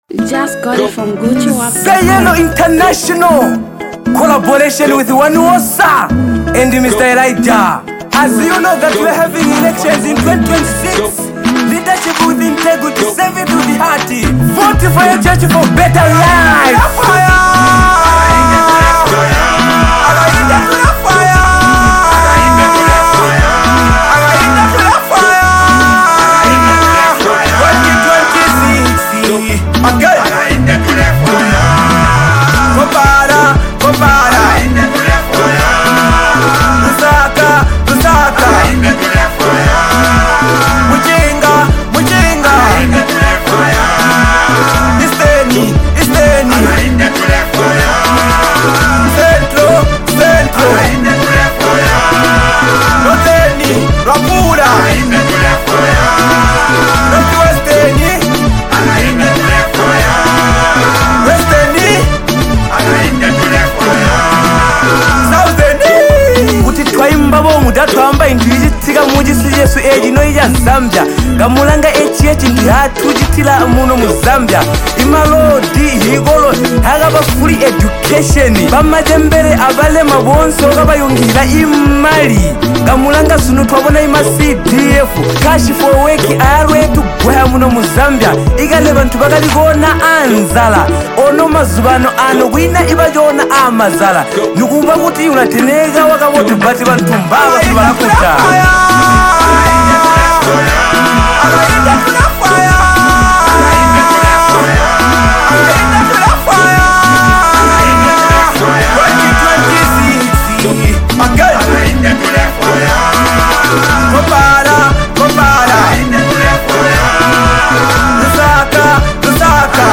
Zambian Mp3 Music
campaign song